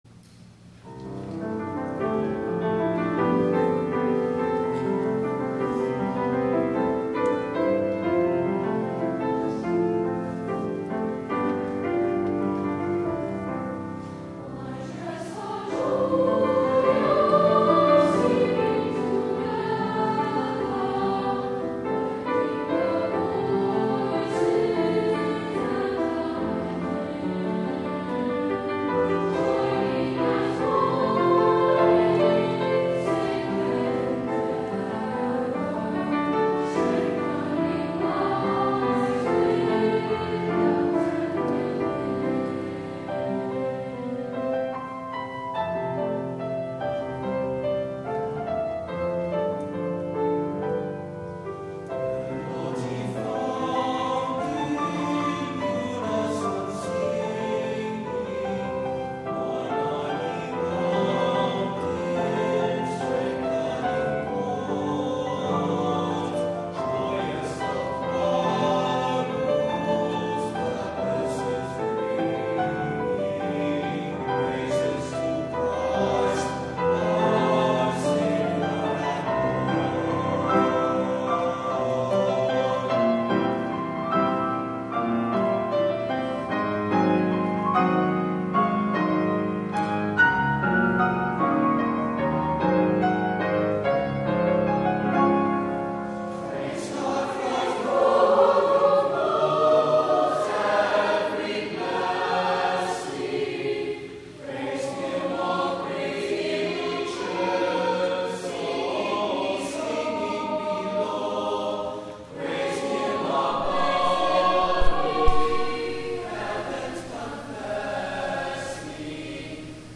THE ANTHEM